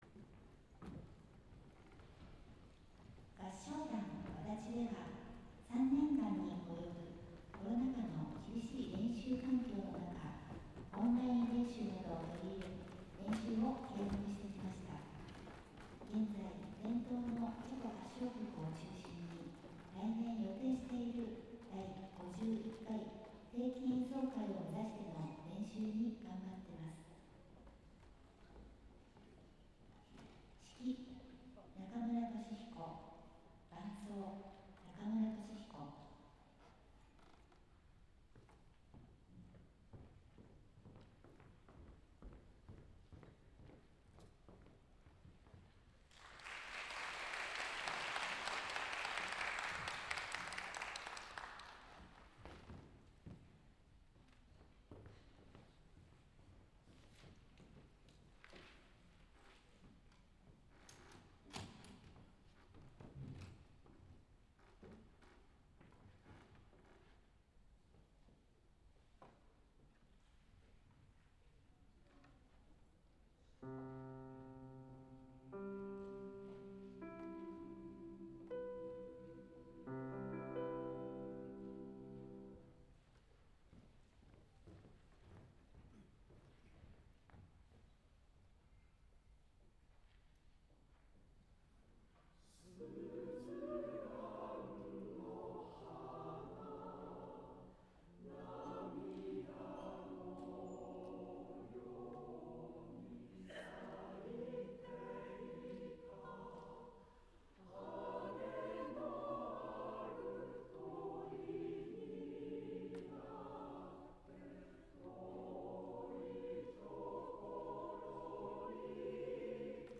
11月 3日(木・祝) 音楽とコーラスの集い 新宿文化ｾﾝﾀｰ大ﾎｰﾙ